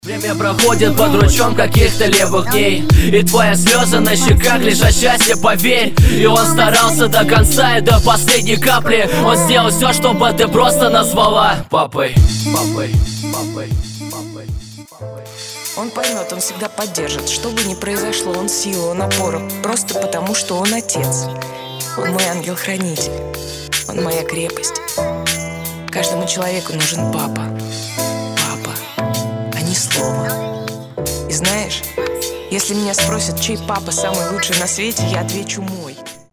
душевные
русский рэп
пианино